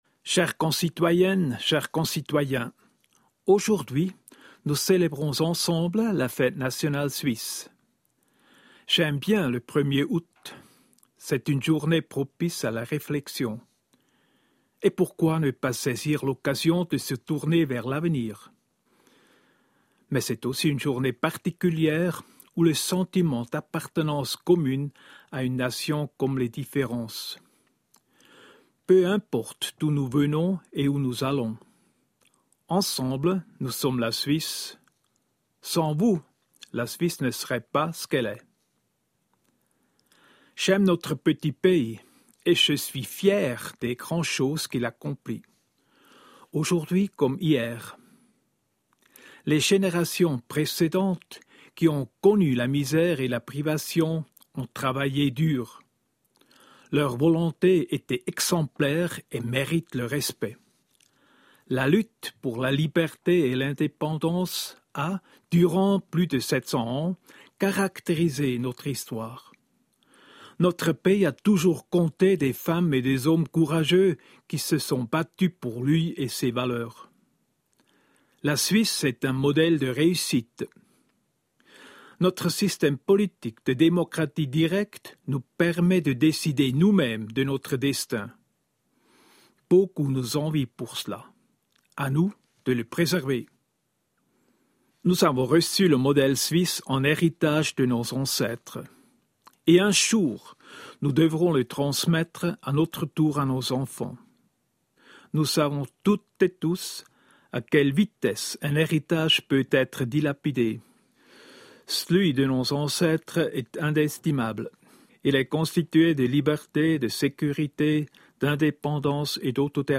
Fête nationale du 1er Août 2019: Message à la Nation du Président de la Confédération
A l’occasion de la Fête nationale du 1er Août, le Président de la Confédération, M. Ueli Maurer, a adressé le message à la Nation ci-dessous: